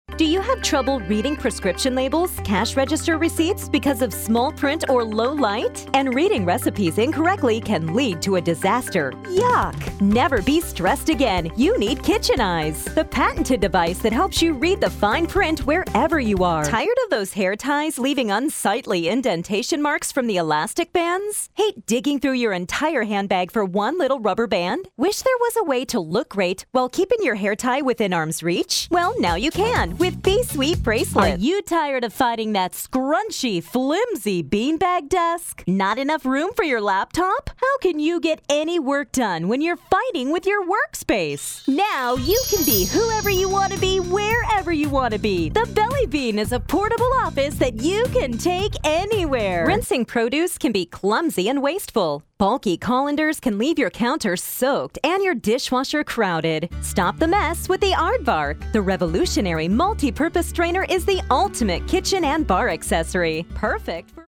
Informercial demo
Young Adult
Middle Aged
INFOMORCIAL DEMO.mp3